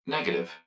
042_Negative.wav